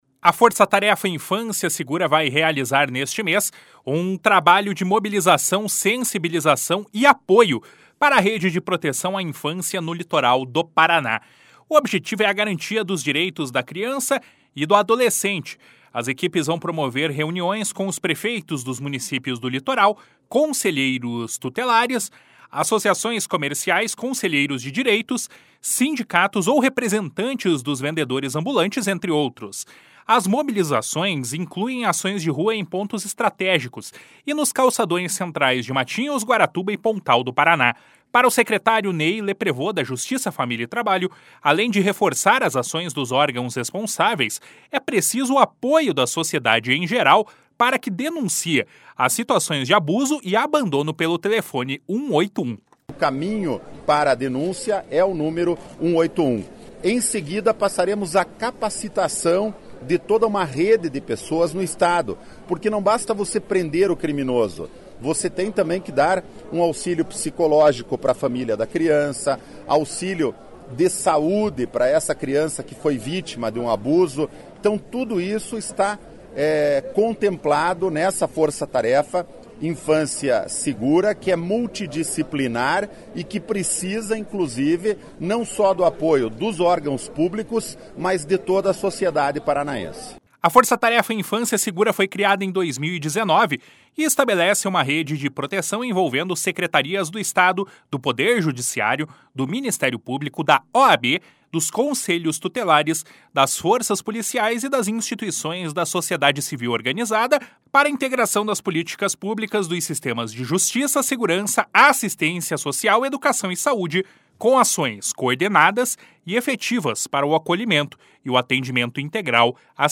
//SONORA NEY LEPREVOST//